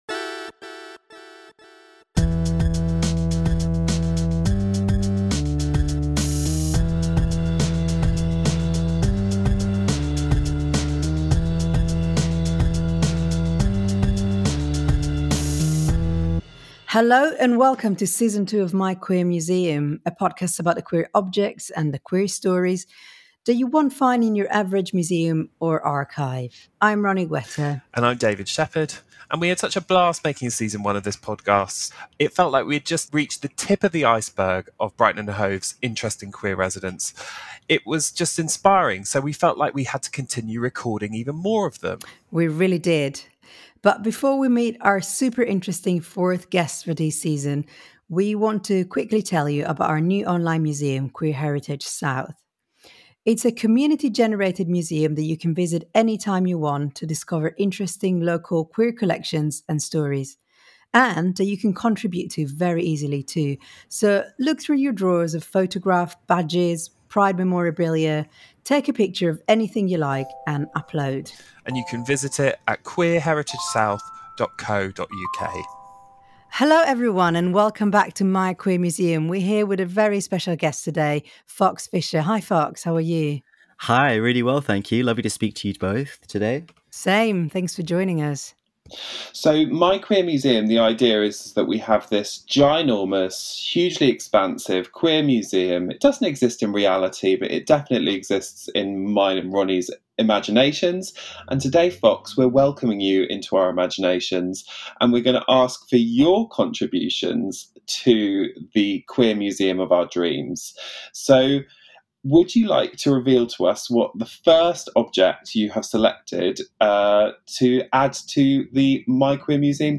In this interview Fox talks about being a young student at Brighton university, about the origins of Trans Pride, and about their political commitment and activism from public to private life. Recorded remotely on August 27, 2021